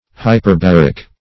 Hyperbaric \Hy`per*bar"ic\, a. [hyper- + baric.]